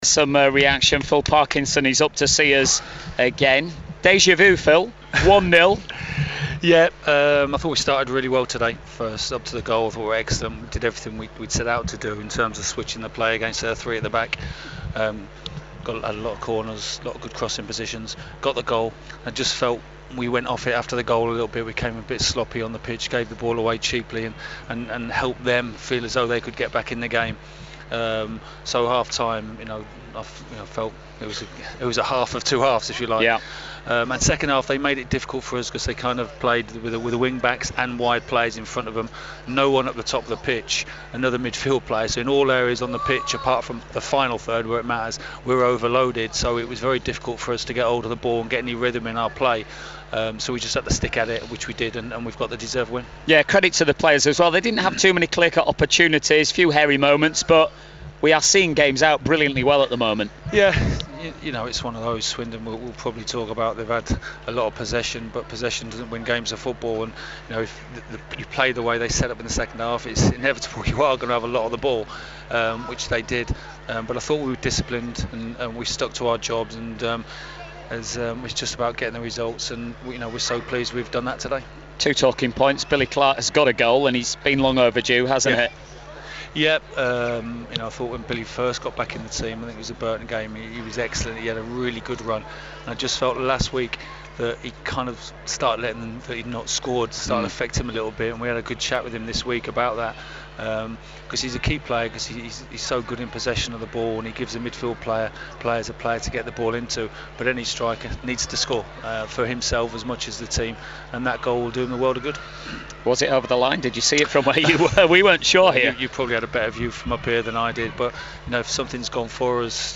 Phil Parkinson Post Match Interview vs Swindon 9th April